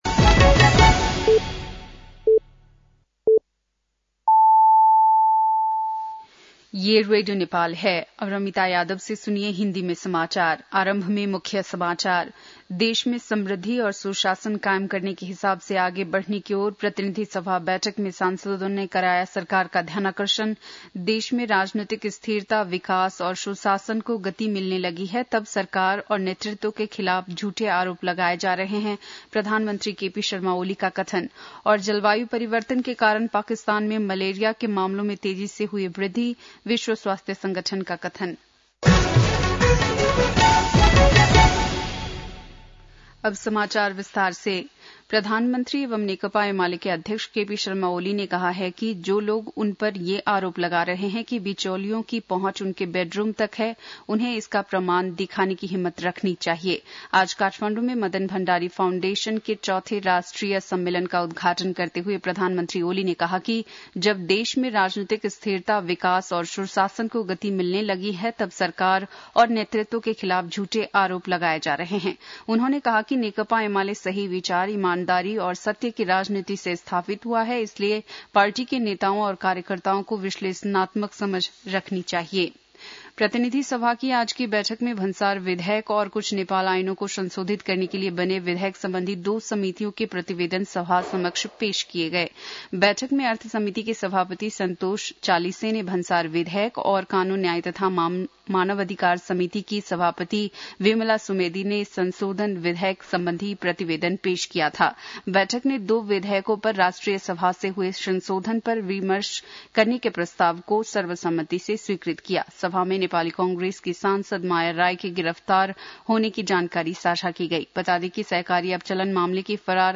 बेलुकी १० बजेको हिन्दी समाचार : १४ वैशाख , २०८२
10-pm-hindi-news-.mp3